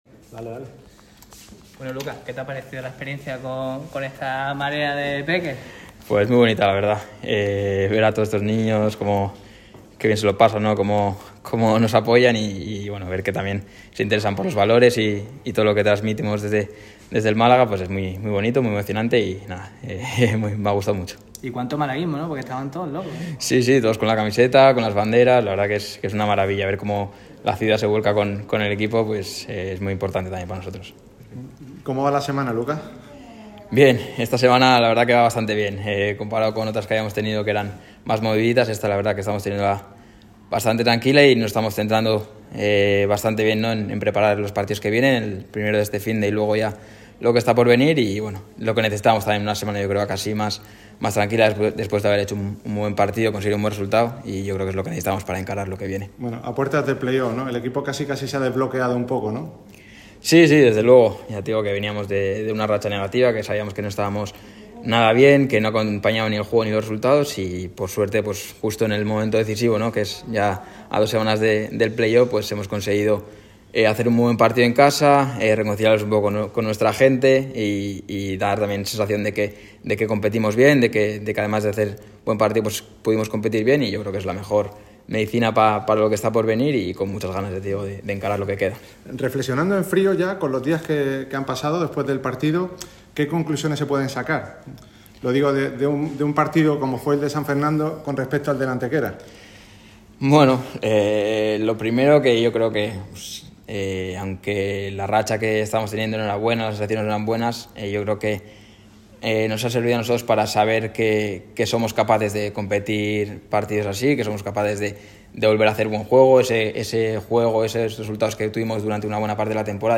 El centrocampista donostiarra ha tomado el turno de palabra en una semana clave para los boquerones. Luca Sangalli, tras Nelson Monte y David Ferreiro, ha sido el jugador elegido como portavoz de la plantilla para lanzar un mensaje positivo de cara a la recta final de temporada.